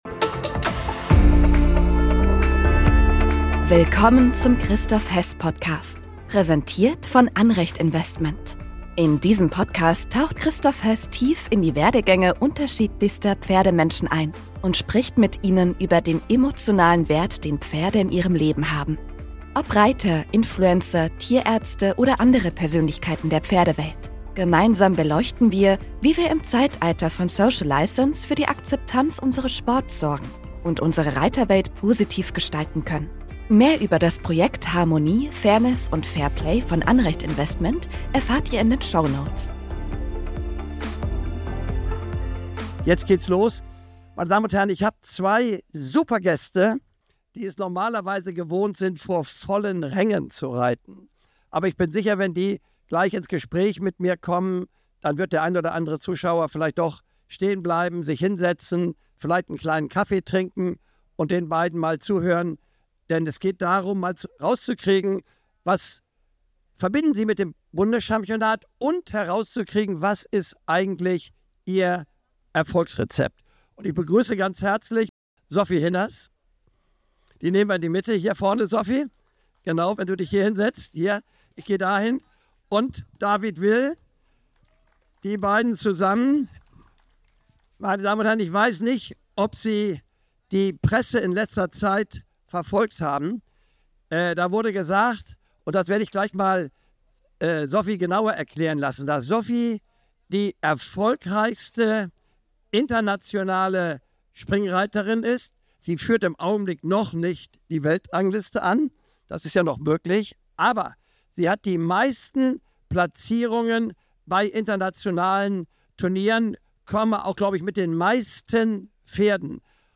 Diese Folge wurde als Live-Podcast bei den Bundeschampionaten in Warendorf im September 2024 aufgezeichnet.